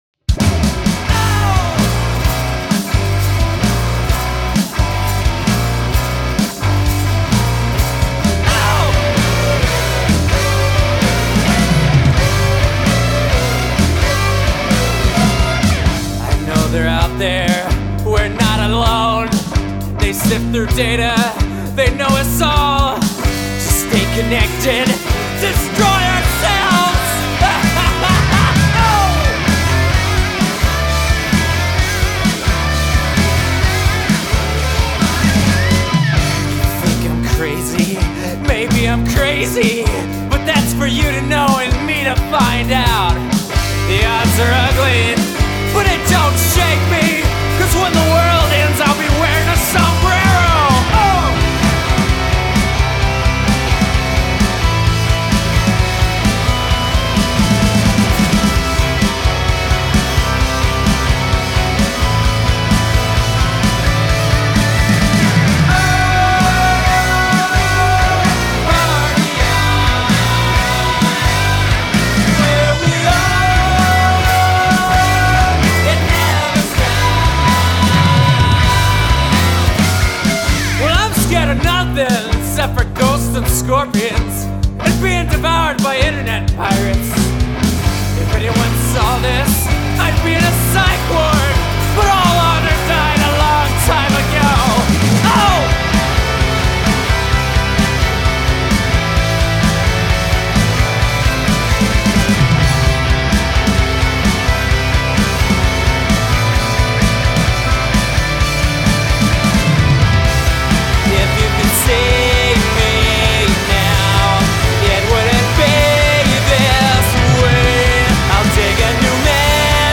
• Recording location:  Avon, Indiana